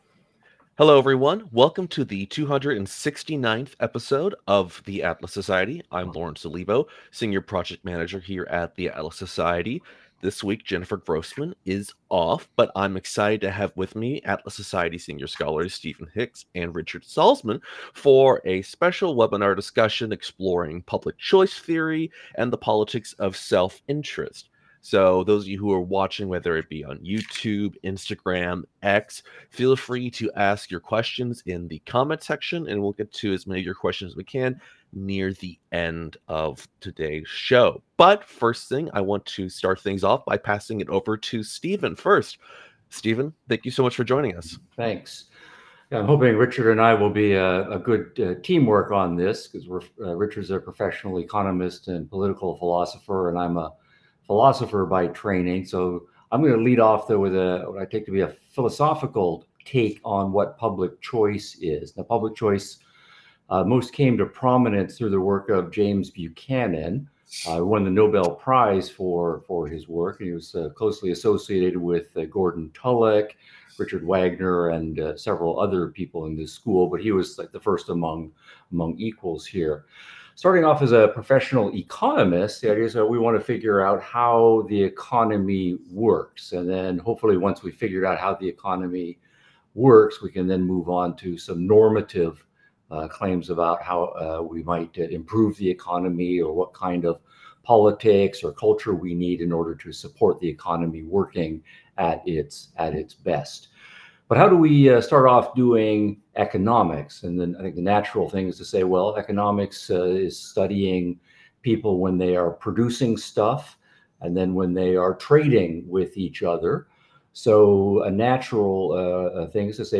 webinar exploring the intersection of “Public Choice” economics and Objectivism.